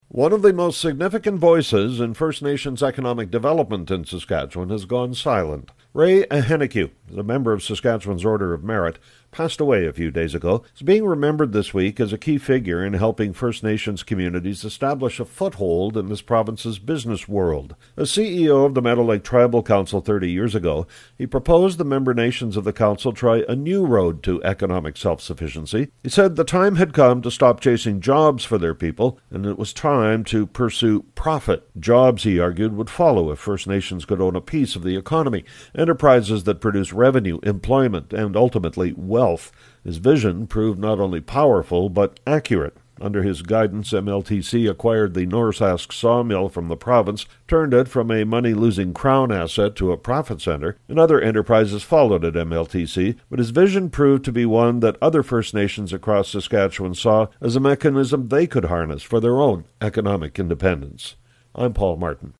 Business Commentary